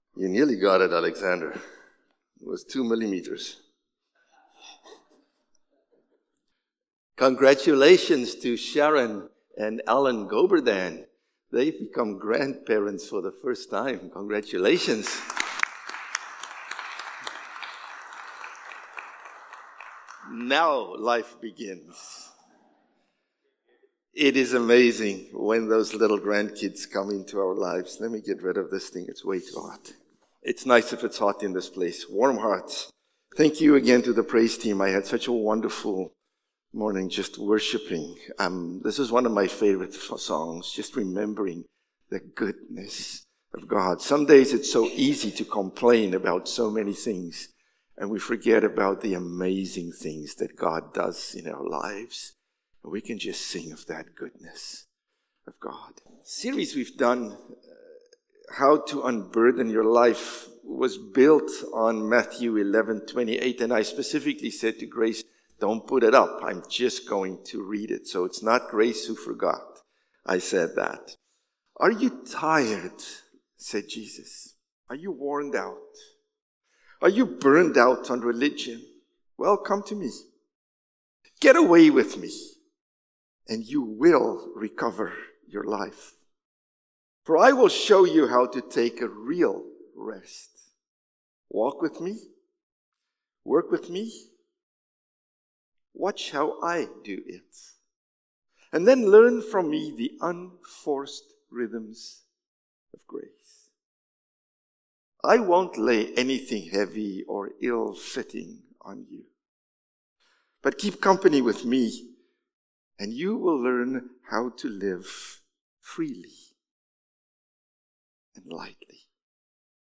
October-27-Sermon.mp3